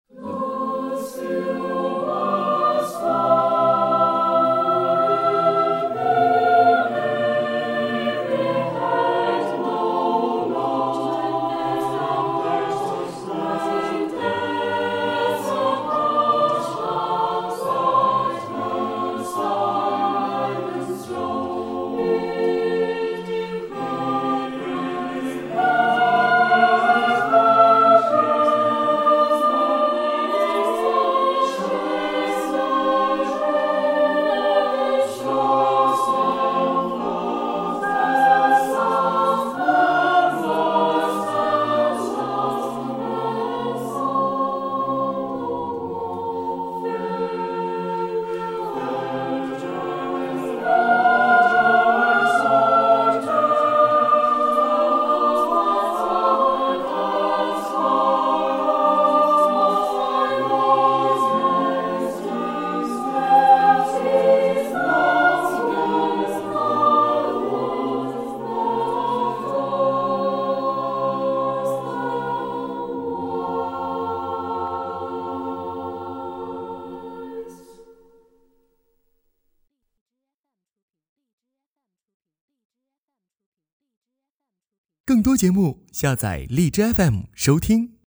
录音地址:石碑胡同中国唱片社录音棚
【欧洲牧歌部分】